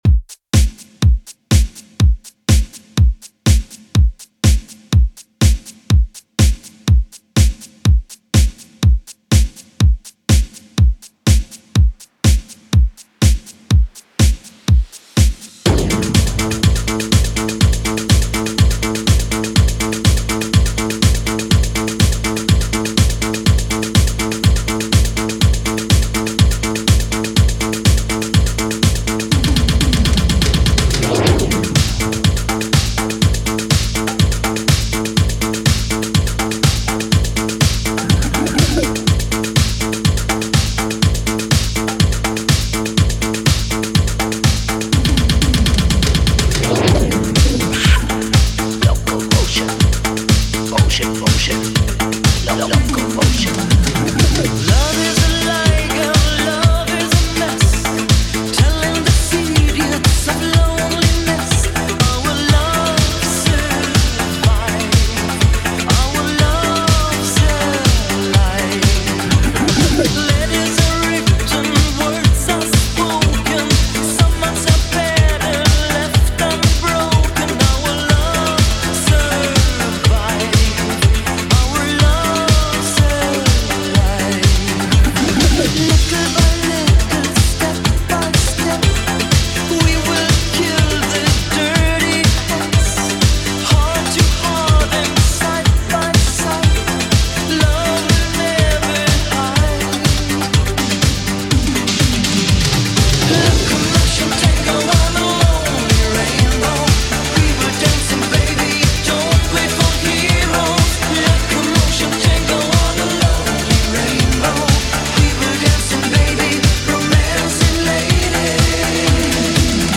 Genre: 80's
BPM: 116